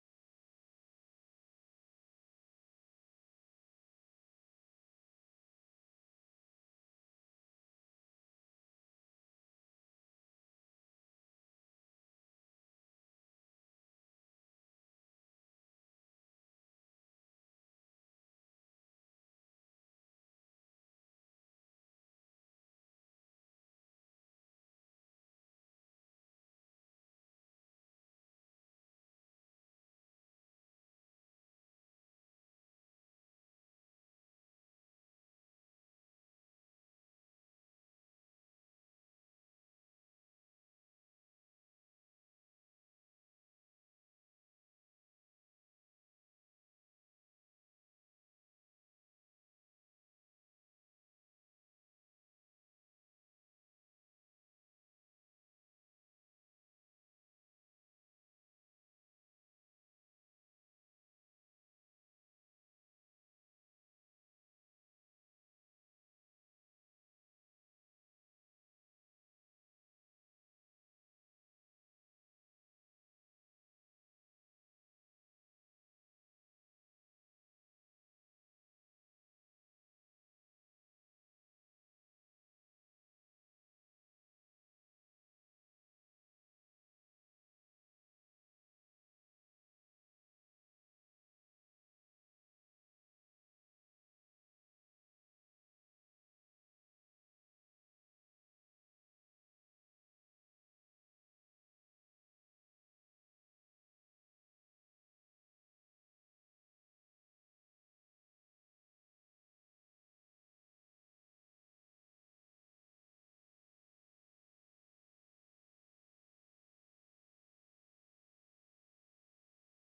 Identitäts-Check: Wer bin ich? 13. Juli 2025 Predigt Evangelien , Matthäus , Neues Testament Mit dem Laden des Videos akzeptieren Sie die Datenschutzerklärung von YouTube.